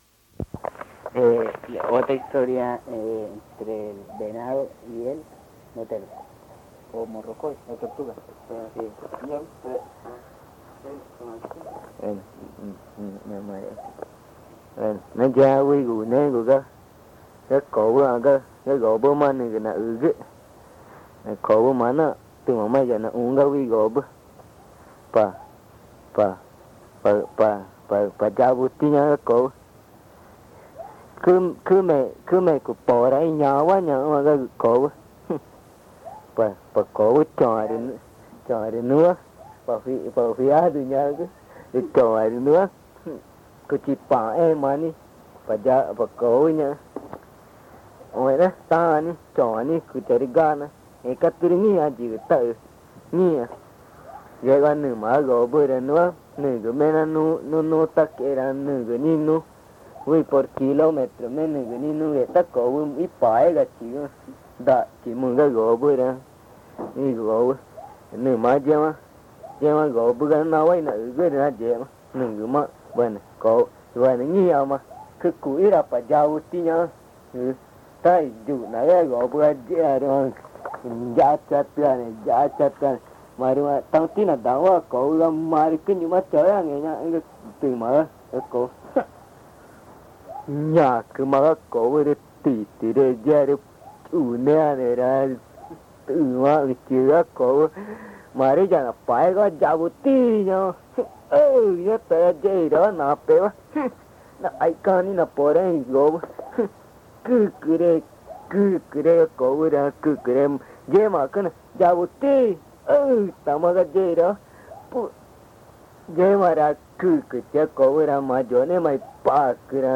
Cuento del venado y la tortuga morrocoy
Boyahuazú, Amazonas (Colombia)